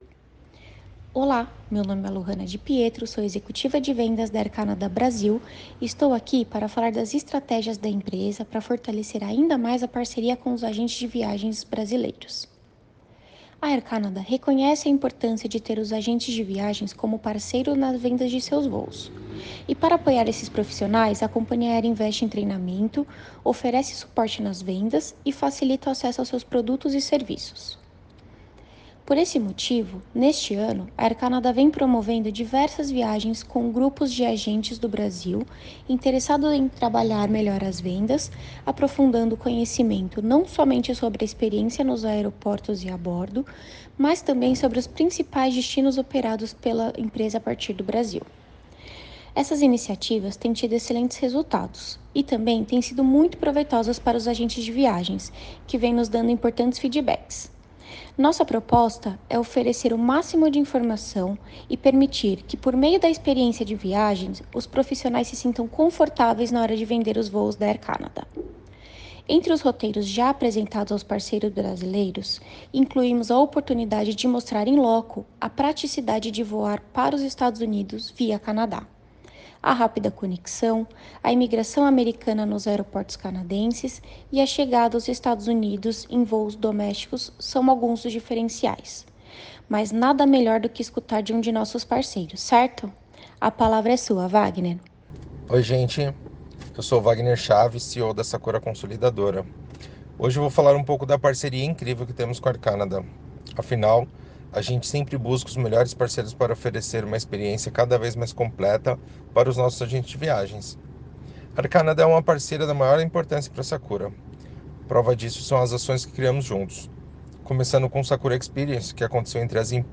com o convidado especial